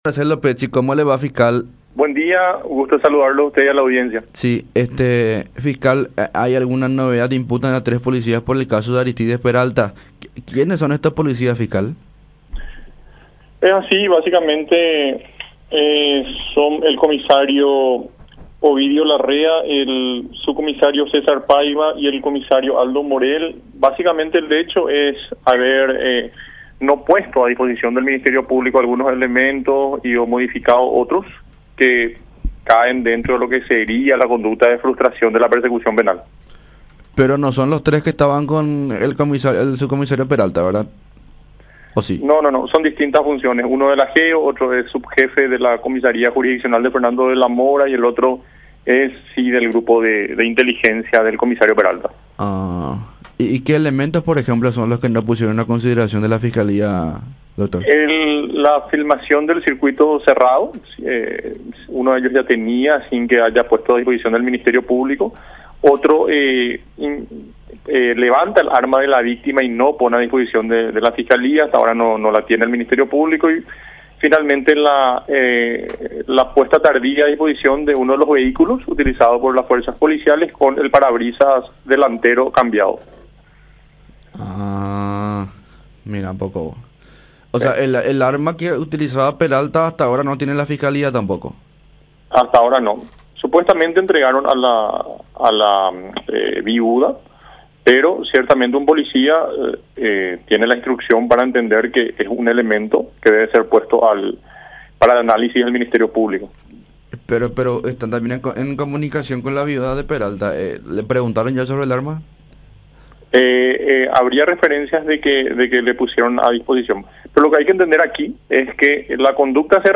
08-FISCAL-MARCELO-PECCI.mp3